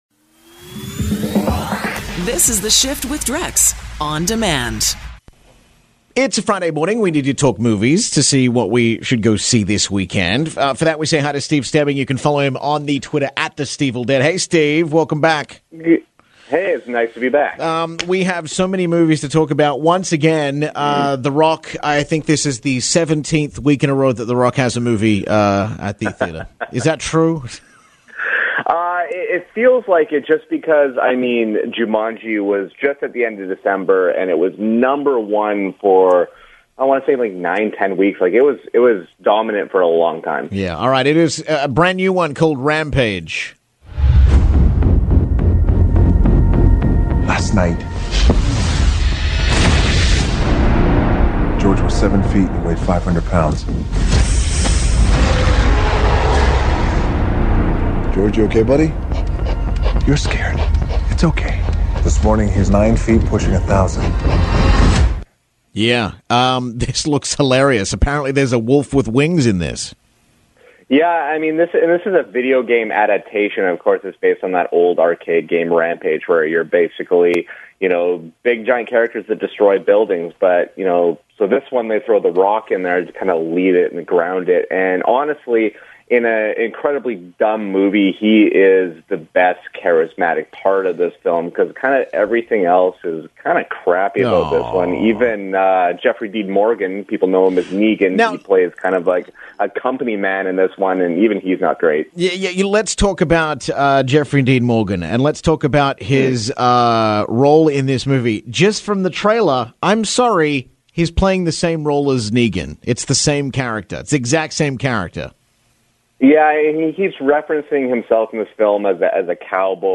Canadian Radio
Movie Reviews